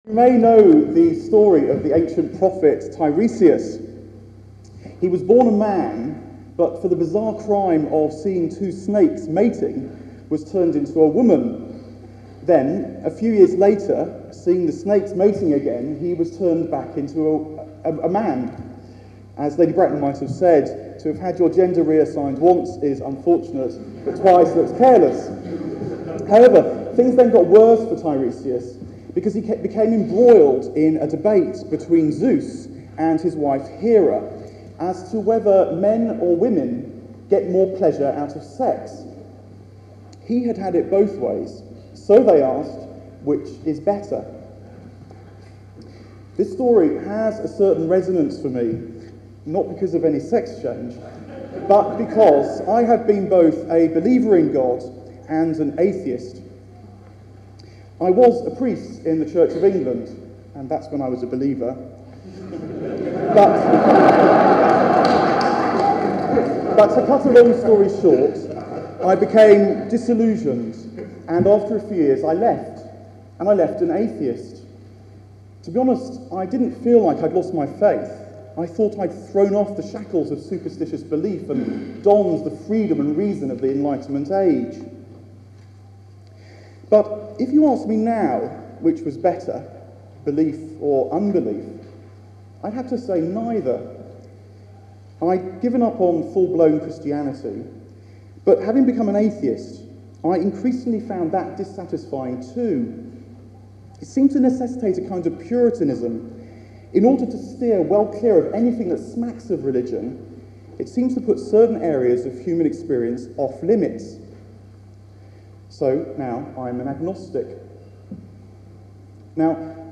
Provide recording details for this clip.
The Lust for Certainty was an event consisting of a series of short talks given by writers in the fields of science, philosophy, politics and religion on Tuesday 21st November 2006 on the dangers of dogmatism in science and religion.